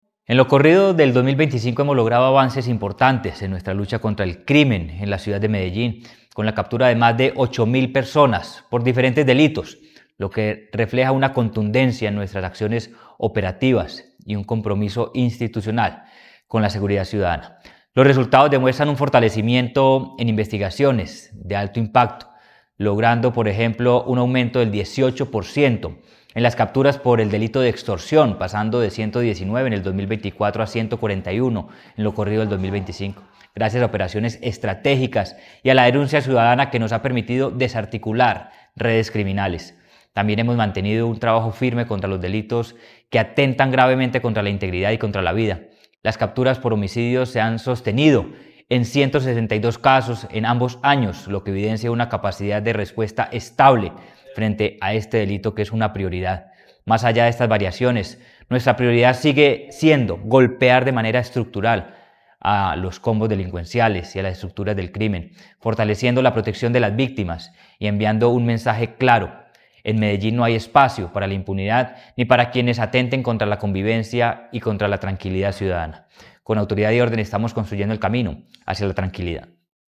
Declaraciones-secretario-del-Seguridad-y-Convivencia-Manuel-Villa-Mejia.mp3